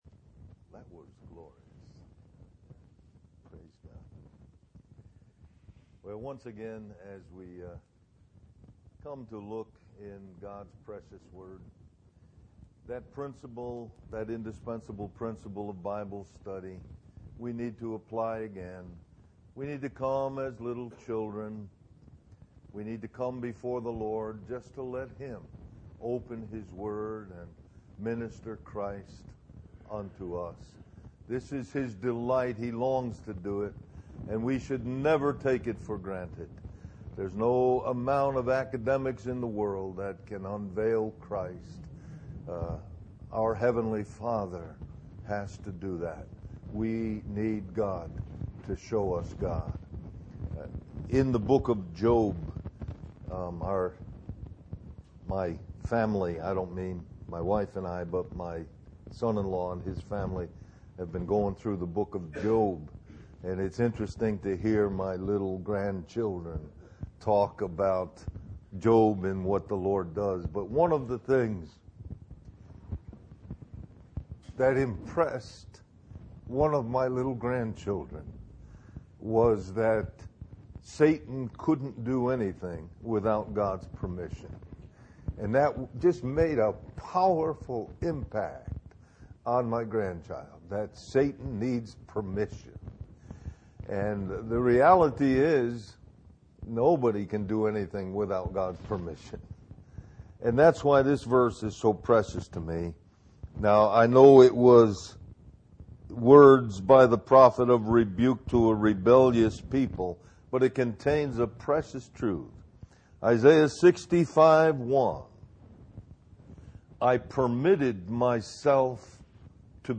A collection of Christ focused messages published by the Christian Testimony Ministry in Richmond, VA.
Del-Mar-Va Men's Retreat